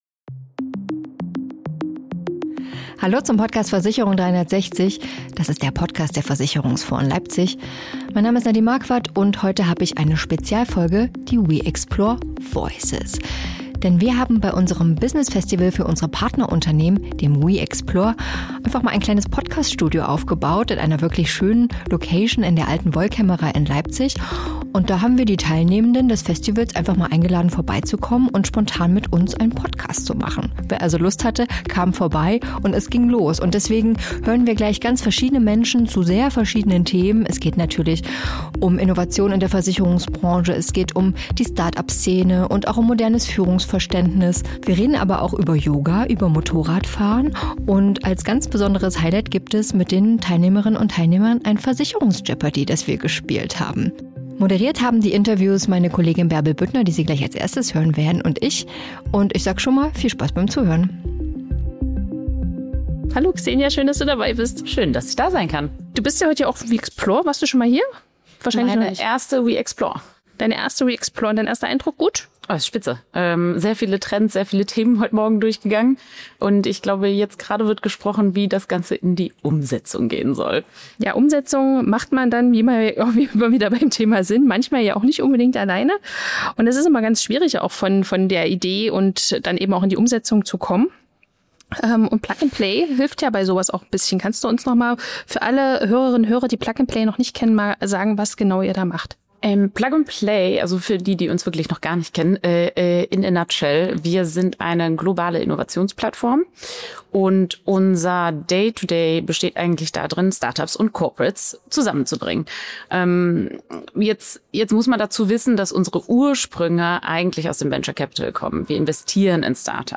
Mit unserem Podcast Versicherung 360 bringen wir Branchenexpertinnen und Branchenexperten zu aktuellen Themen der Versicherungswirtschaft zusammen. In dieser Spezialfolge we.Xplore Voices erwarten Sie spontane Gespräche mit Gästen unseres Business-Partnerfestivals.